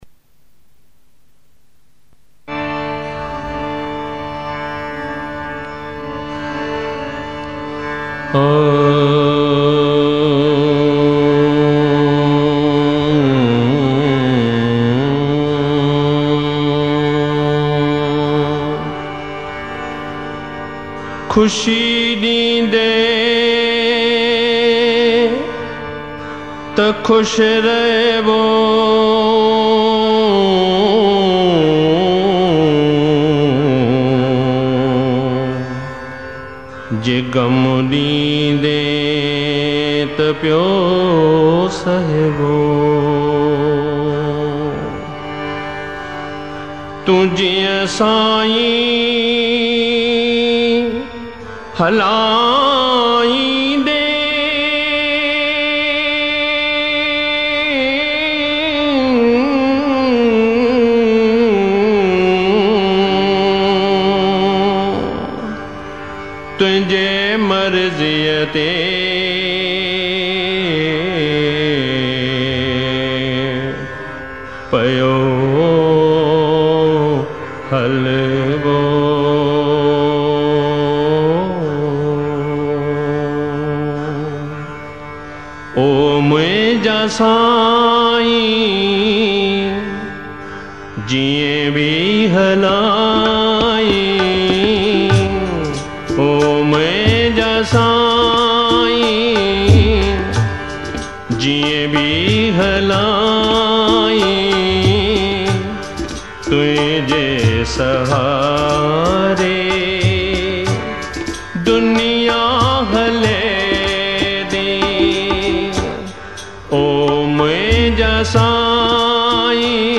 Classical Spiritual Sindhi Songs